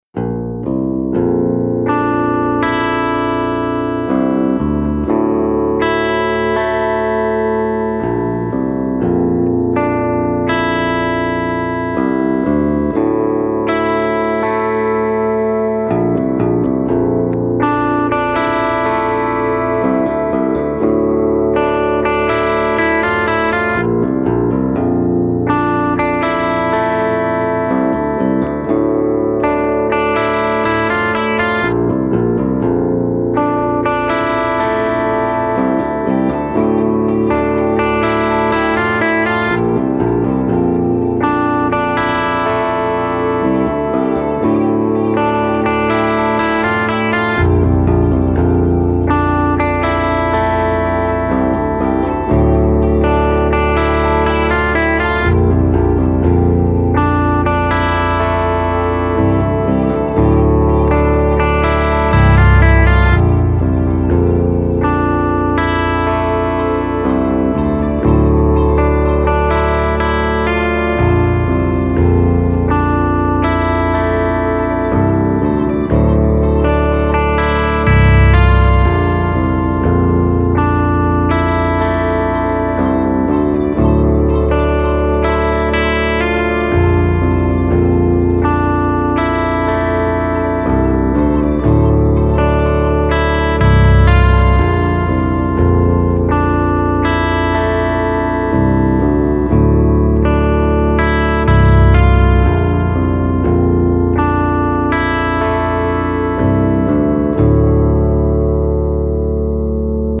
not looped